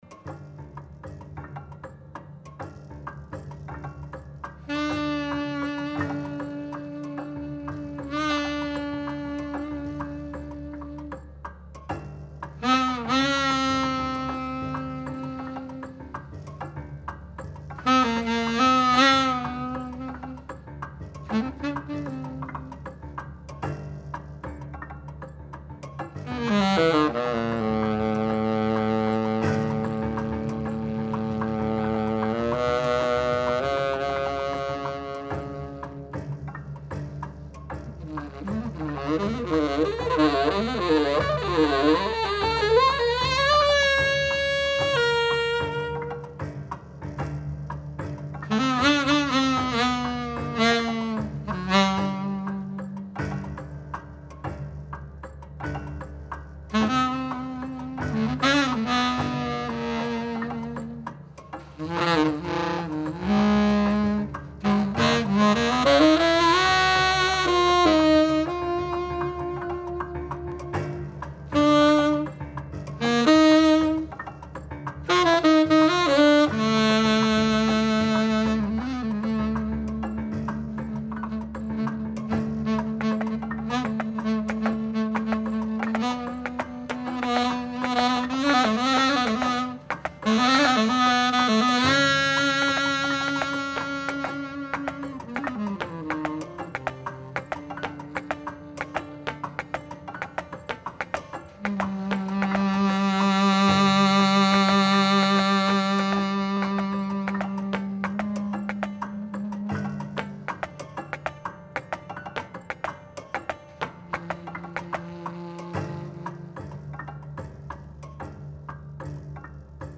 basée sur un chant pigmée.
(Impro Tenor sax)